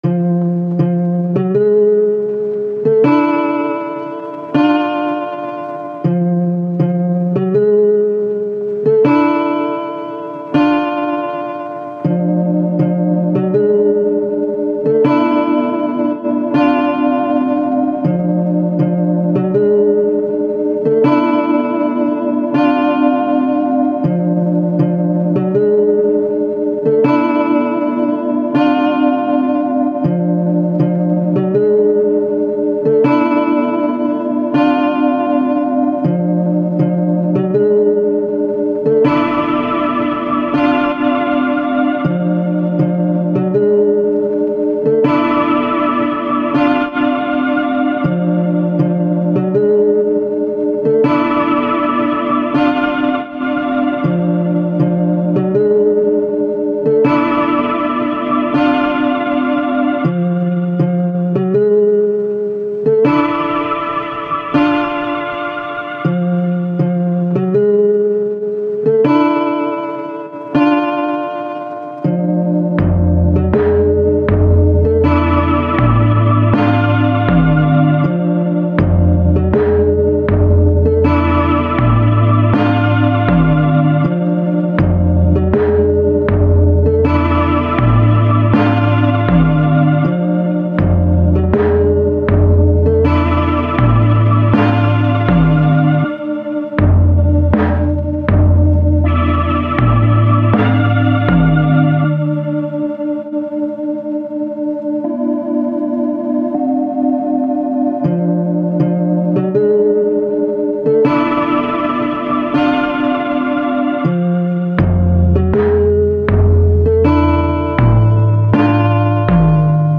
ambient music for gloomy vibes.